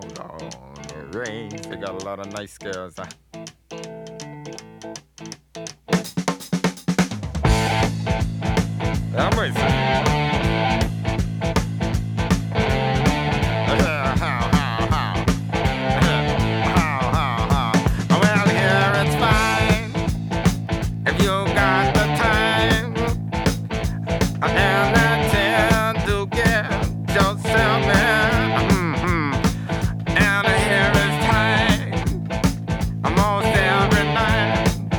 Жанр: Рок / Пост-хардкор / Хард-рок
# Hard Rock